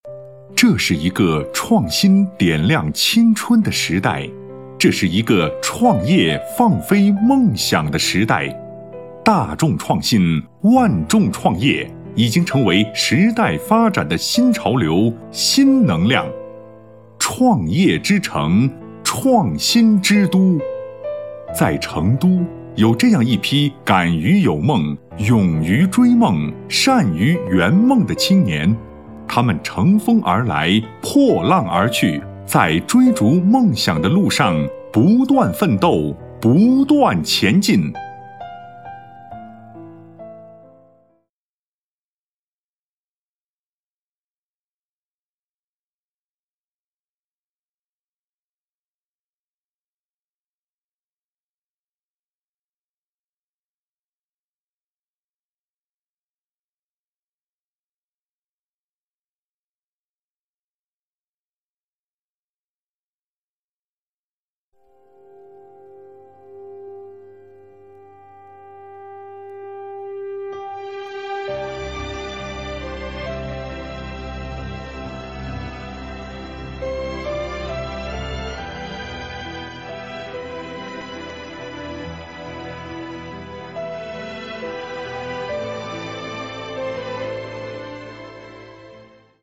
配音风格： 时尚 可爱 活力 大气 稳重 成熟 甜美 温柔 知性 亲切 温柔 知性 亲切